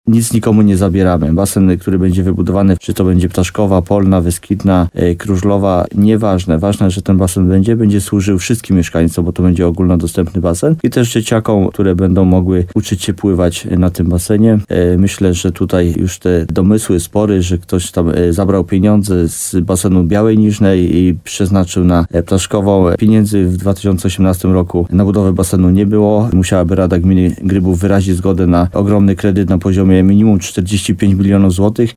Wójt gminy Grybów Jacek Migacz przypomina, że decyzja o budowie basenu w Ptaszkowej powinna zakończyć nieporozumienia w tej kwestii, które narosły w ciągu ostatnich lat.